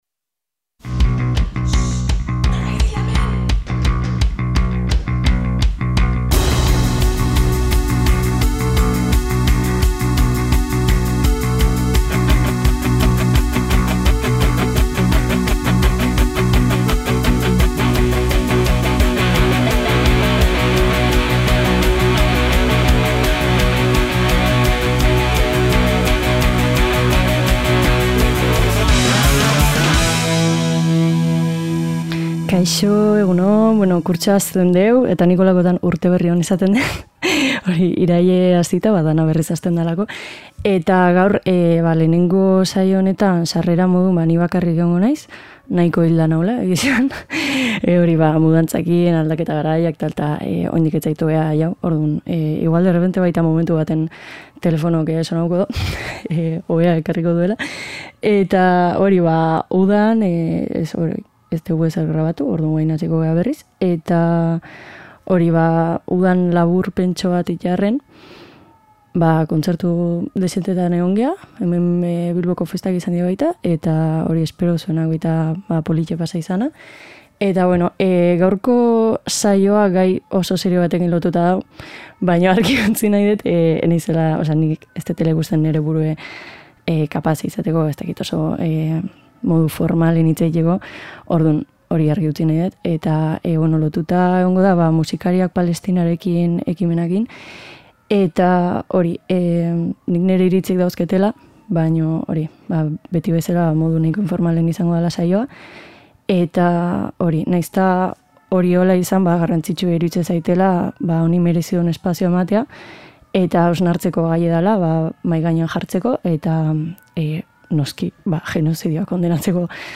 Musikariak Palestinarekin ekimenera batu eta boikotari buruzko saioa ekarri dugu, 30.a. Gure kontsumo ohiturak eraldatzearekin lotuta, formatu fisikoetan “galduta” zeuden batzuk berreskuratu ditugu. Zerrenda Euskal Herriko 80. eta 90. hamarkadetako maketen arteko aukeraketa bat da.